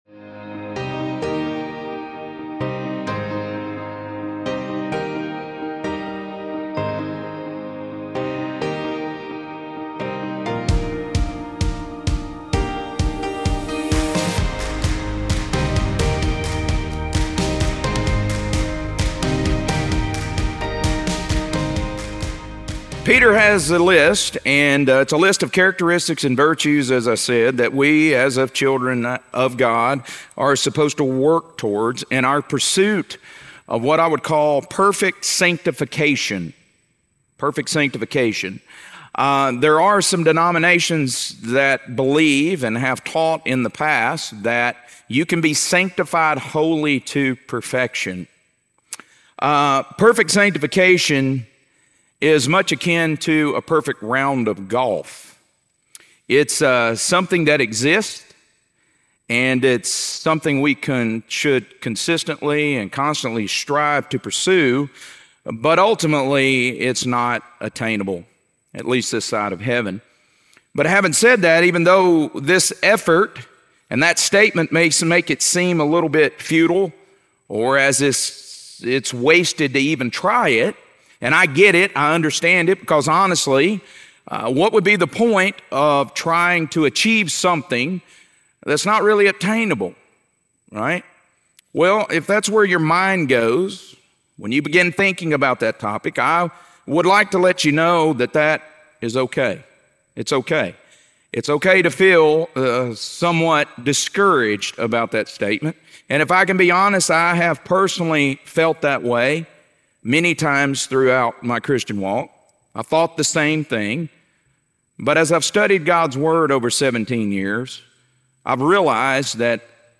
2 Peter - Lesson 1E | Verse By Verse Ministry International